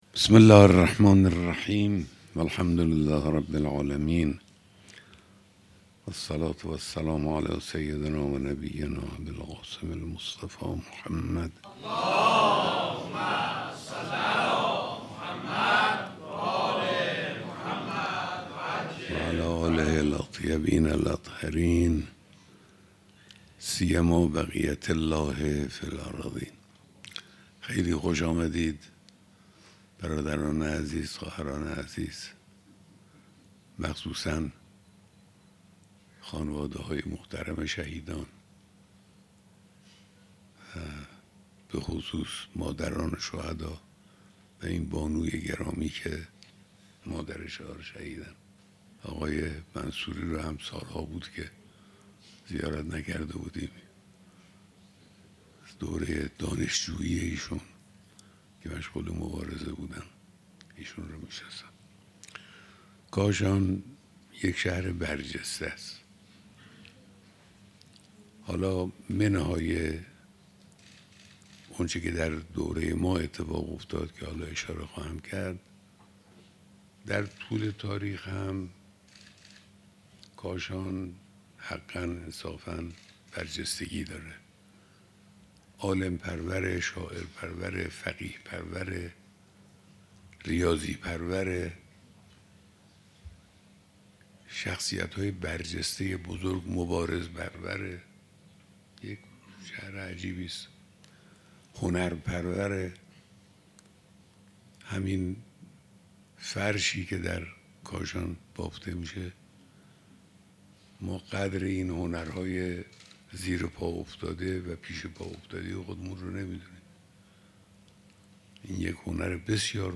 بیانات رهبر معظم انقلاب اسلامی در دیدار دست‌اندرکاران برگزاری کنگره ملّی بزرگداشت یک هزار و هشتصد و هشتاد شهید کاشان که در تاریخ ۲۶ دی ۱۴۰۳ برگزار شده بود، امشب (پنجشنبه، ۴ بهمن ۱۴۰۳) در محل برگزاری همایش در کاشان منتشر شد.